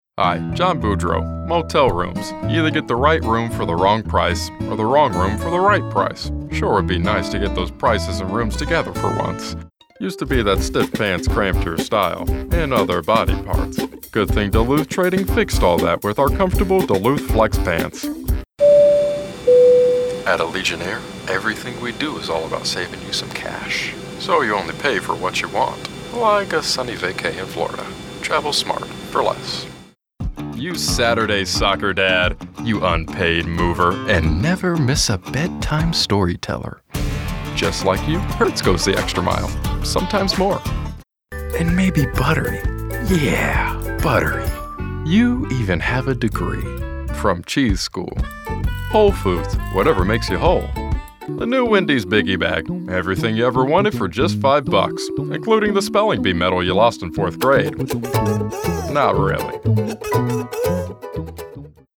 Commercial Voiceover Demo